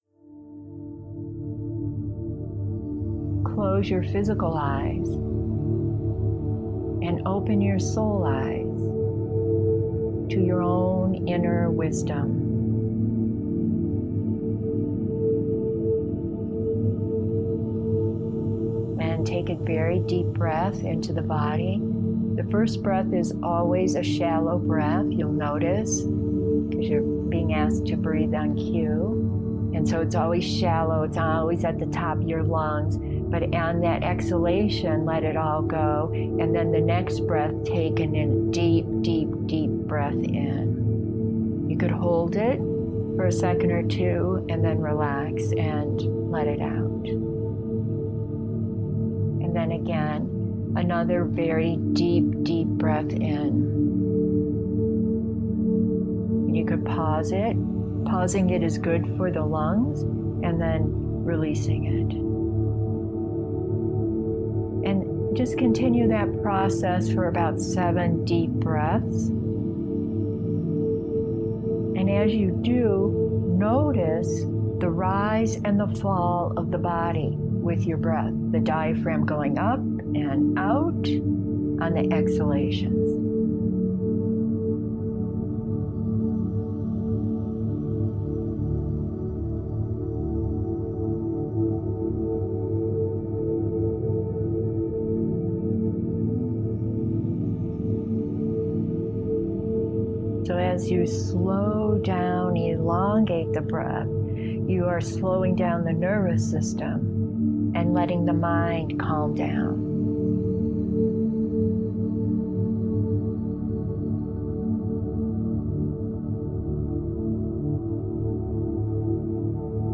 New Inner Peace Guided Practice